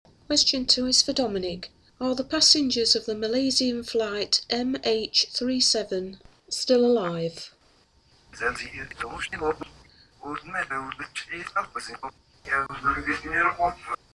I get a very interesting response – it sounds like a discussion with the pilot.